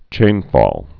(chānfôl)